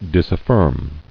[dis·af·firm]